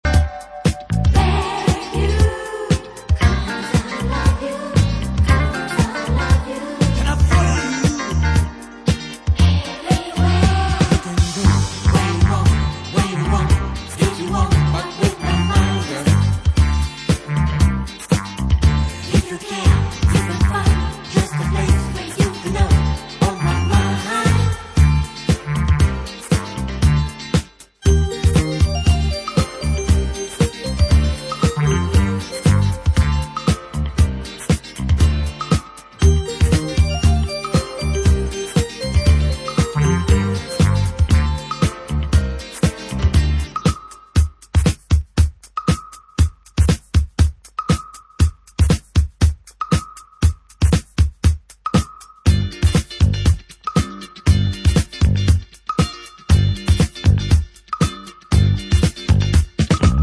Italodisco re-issue. Early italian boogie
with a funny italian accent.